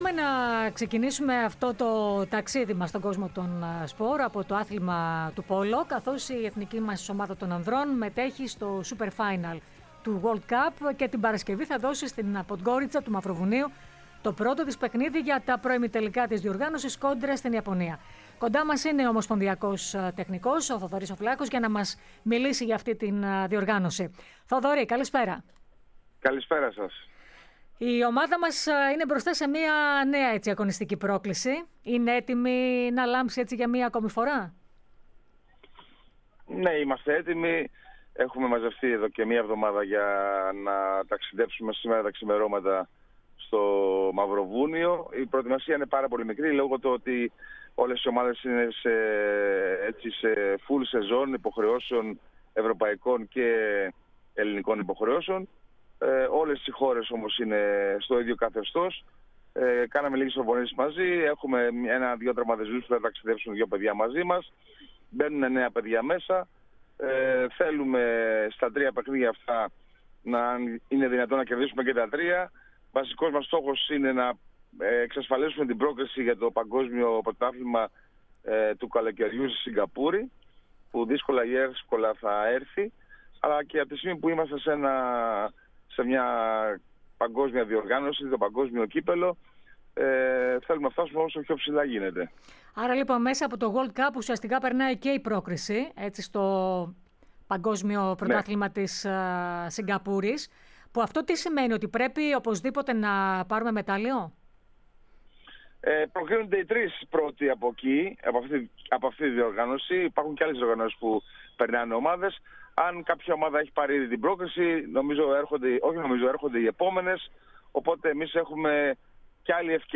Έναν πολύ σημαντικό καλεσμένο είχε -έστω και τηλεφωνικά- η εκπομπή “Citius Altius Fortius” της ΕΡΑ ΣΠΟΡ.